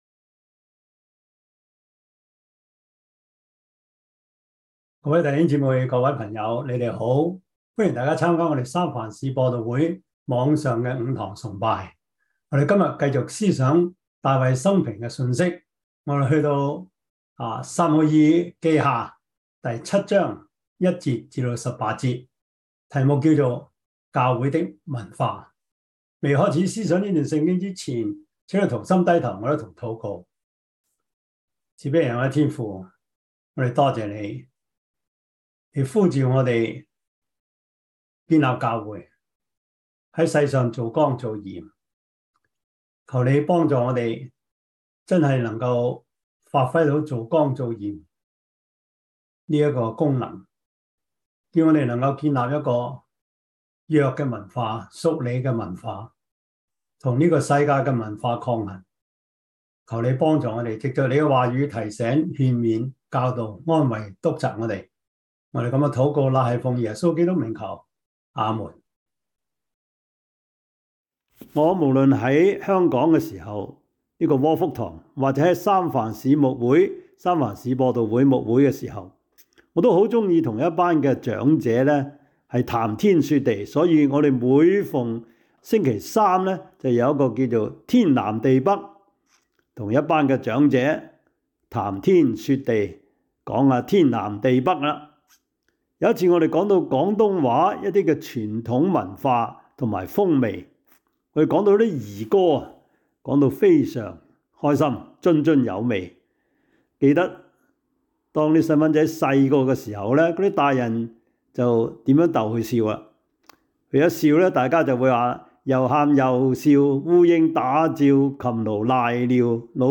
撒母耳記下 7:1-18 Service Type: 主日崇拜 撒母耳記下 7:1-18 Chinese Union Version